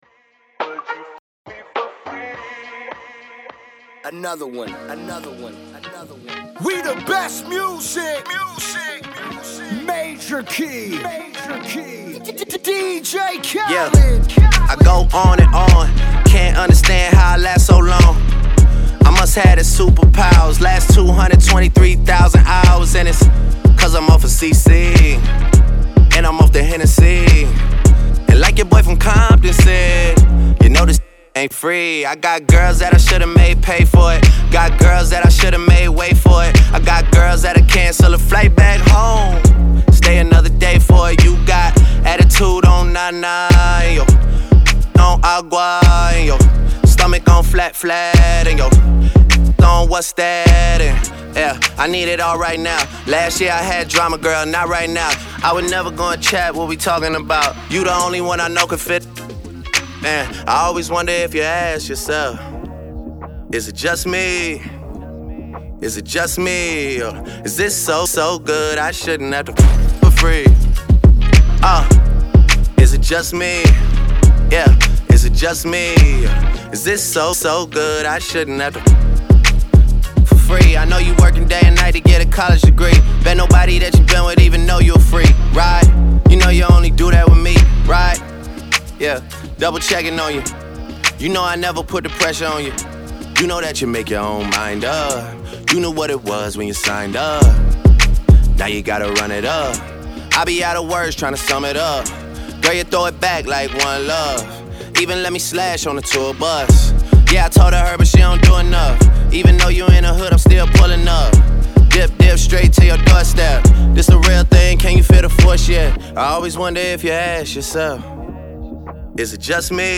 Hiphop
NEW RADIO VERSION